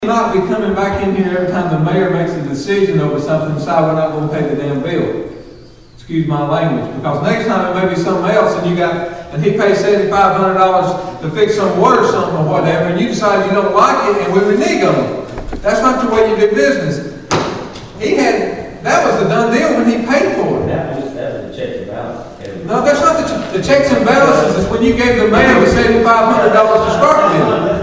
City Finances Draw Heated Exchange Between Councilmen
There were several outbursts among the Councilmen over the controversial issue.